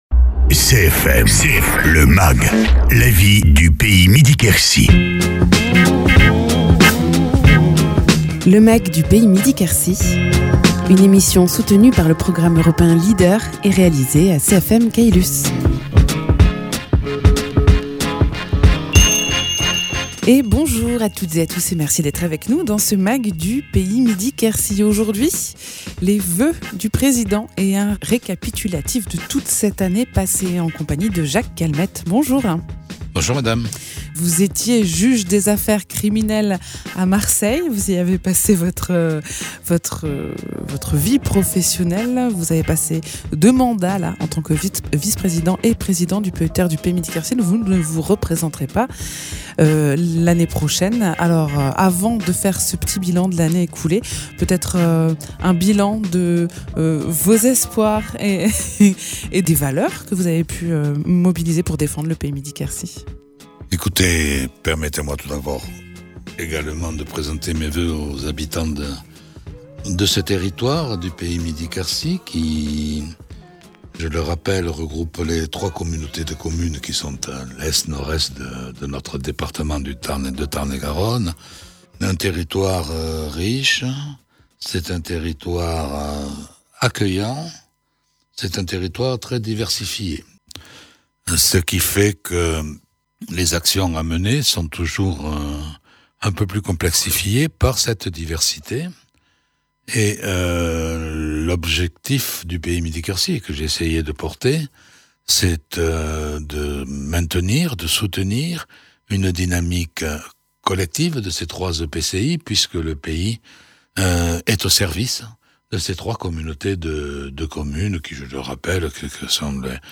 Invité(s) : Jacques Calmettes, président du PETR Pays Midi-Quercy